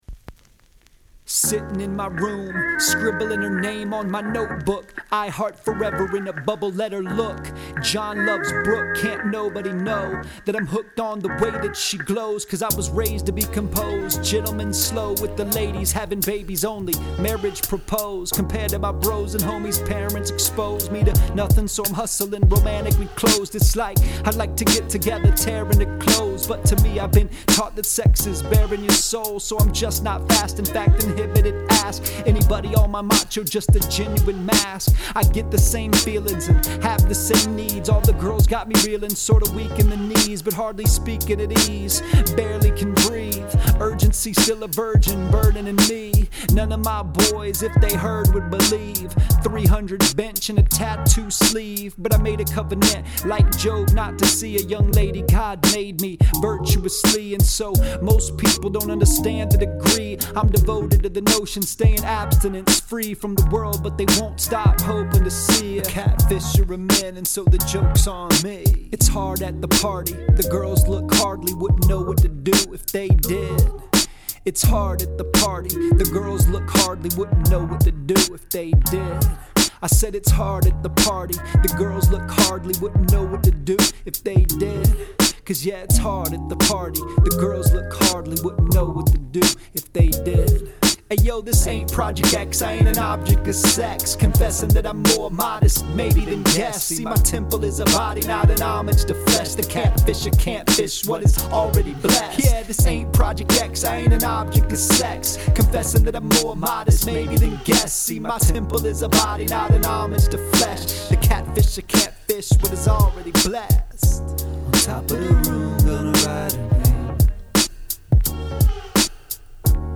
Rap Song